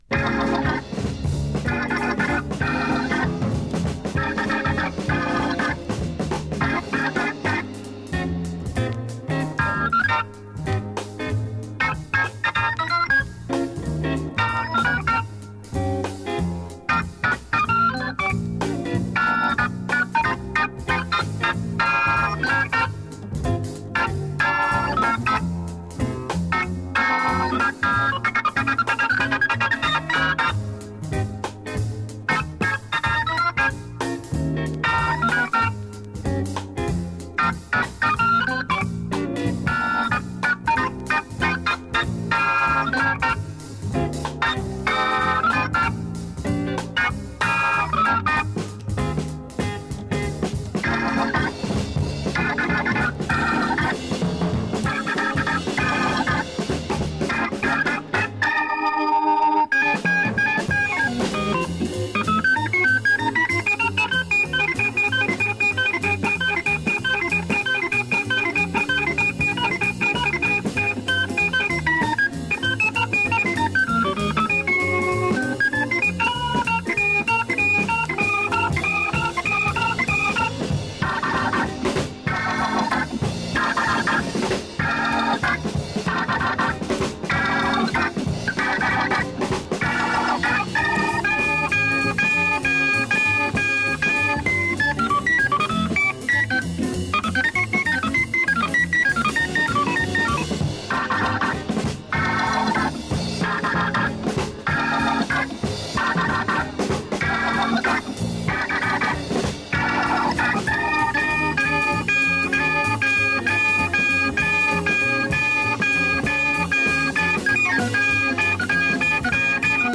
Hammond Organ